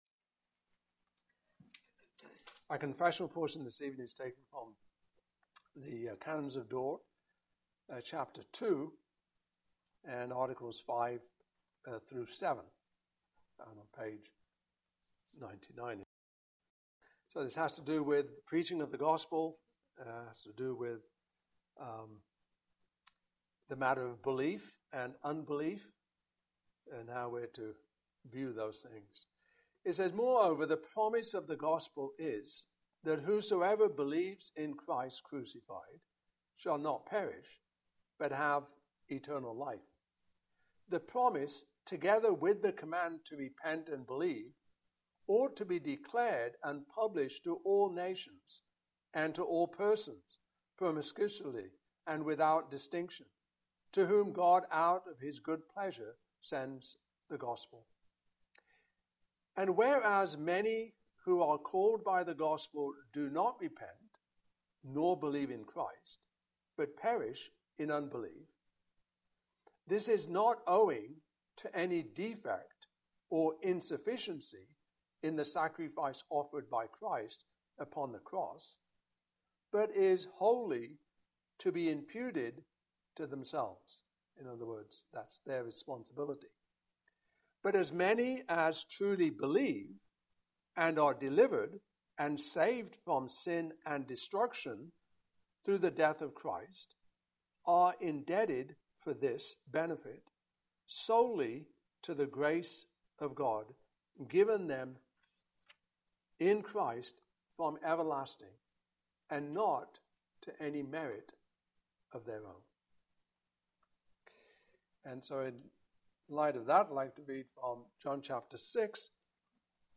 Passage: John 6:41-71 Service Type: Evening Service